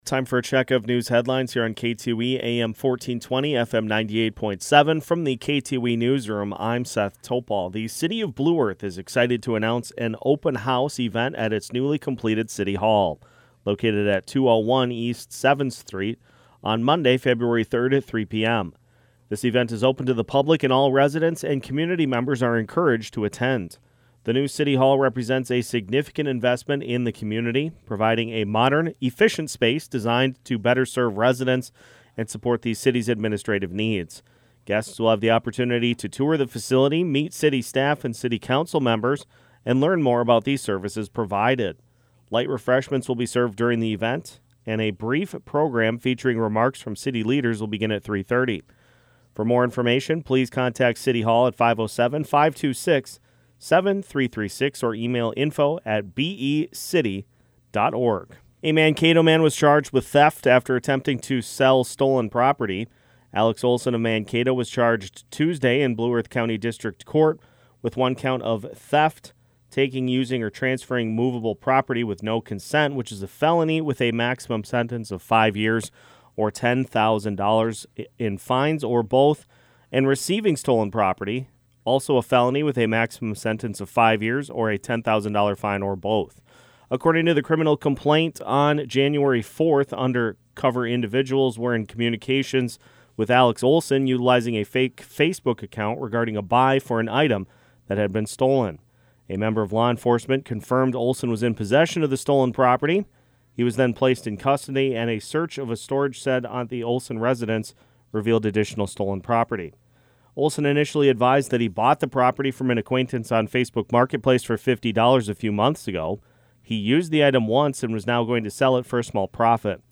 KTOE Noon Newscast 1-8-25